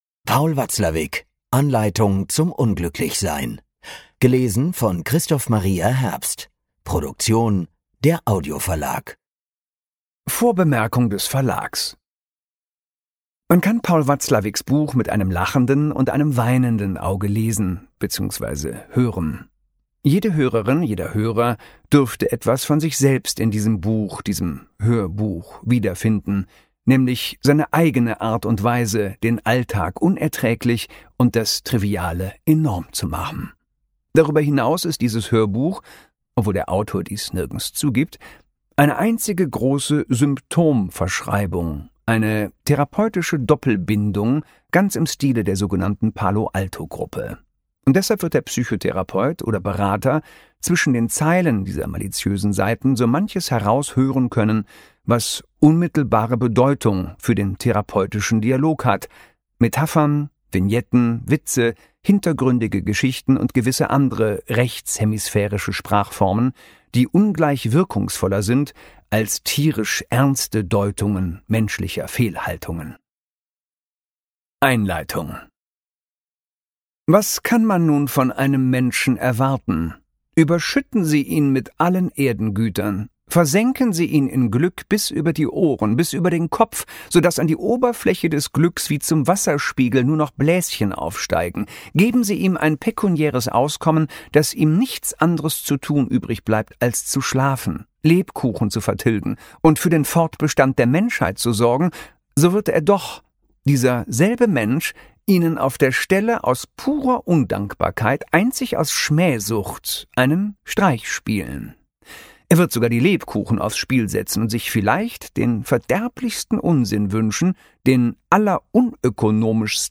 Ungekürzte Lesung mit Christoph Maria Herbst (2 CDs)
Christoph Maria Herbst (Sprecher)